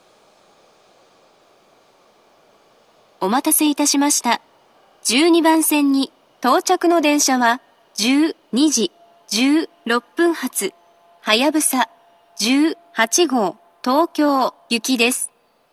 １２番線到着放送